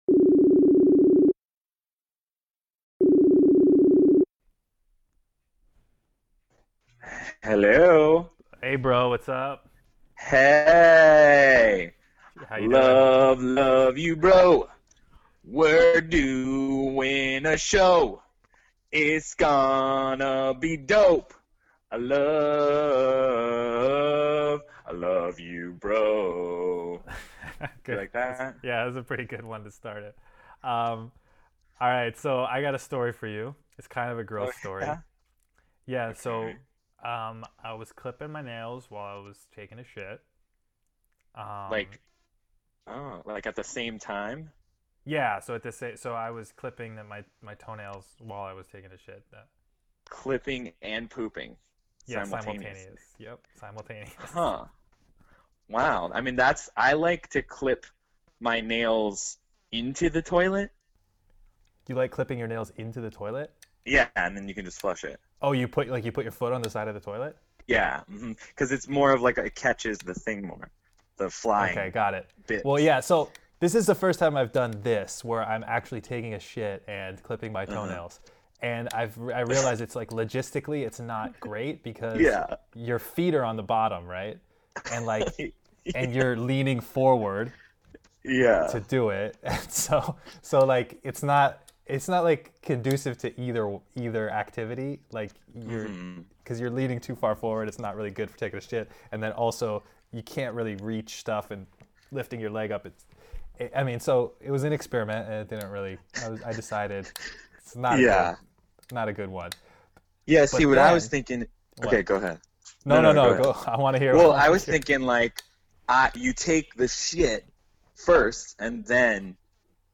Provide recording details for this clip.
living on opposite sides of the world catch up on the phone, have fun arguments, and more.